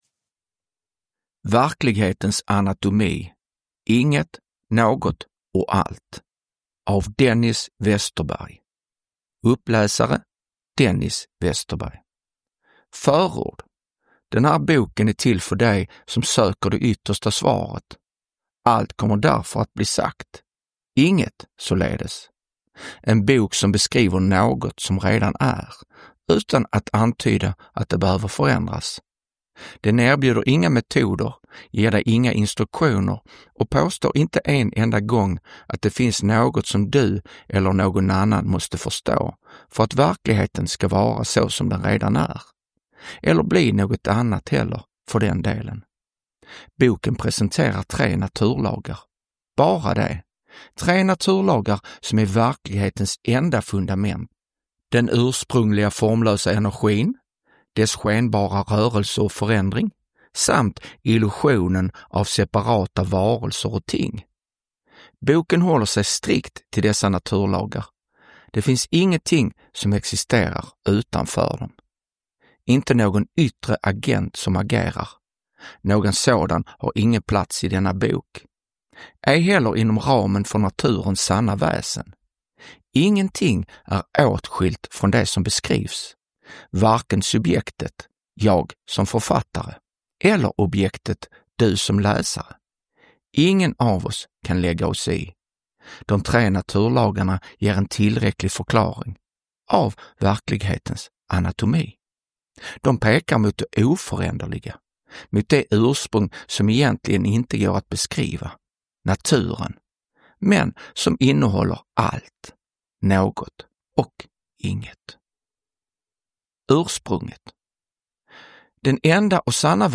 Verklighetens anatomi : Inget, något och allt (ljudbok) av Dennis Westerberg